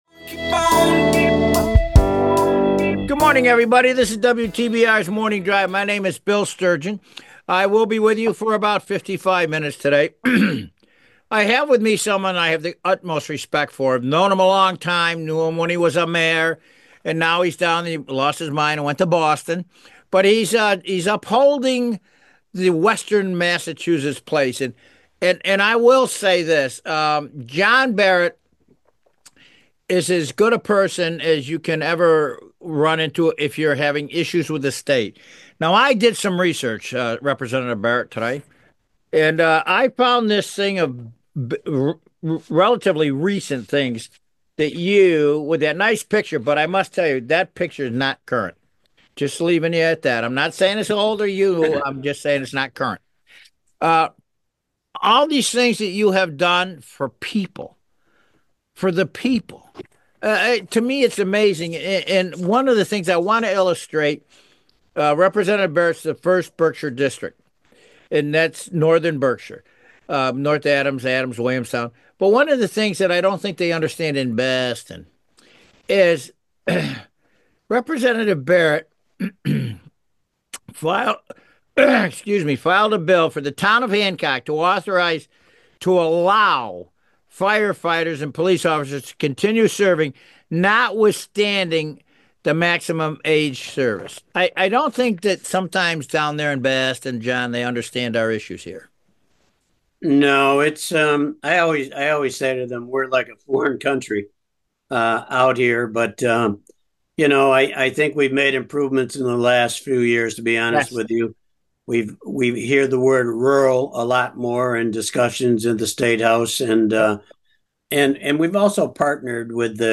talks with State Representative John Barrett for the hour.